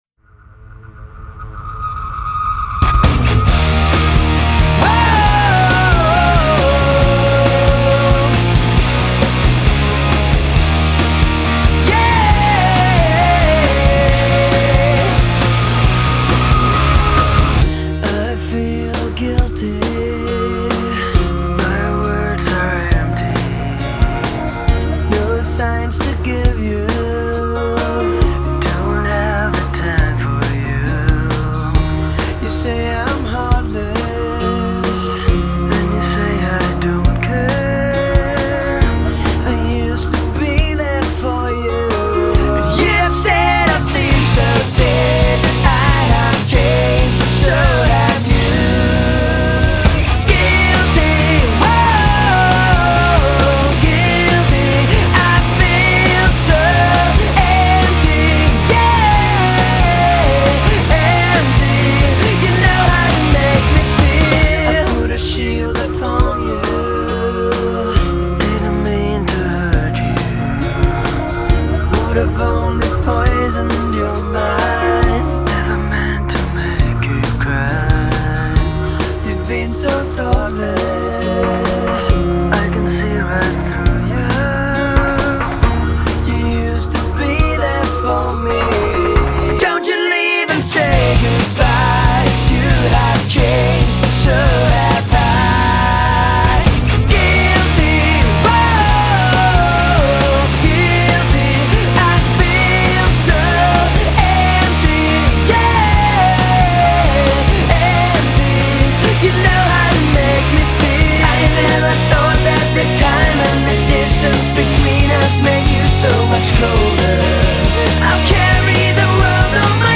Категория: Rock, Alternative